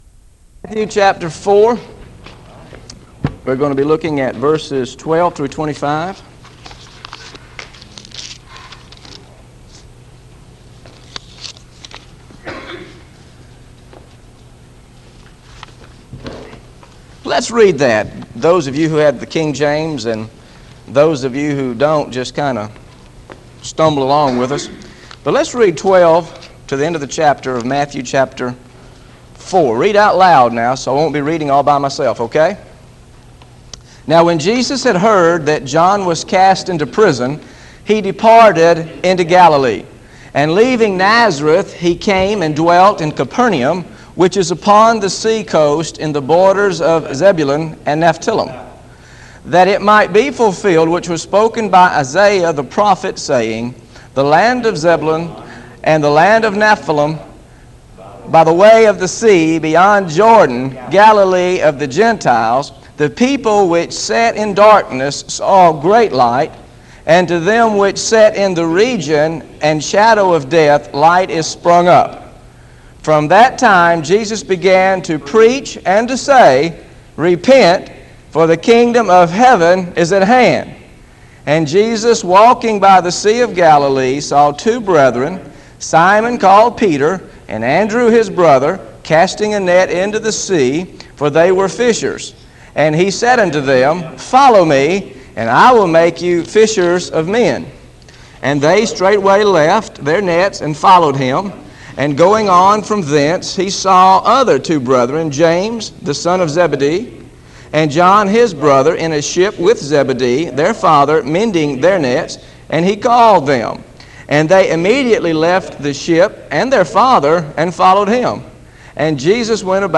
Listen to Matthew 4 Verses 12-25 Teaching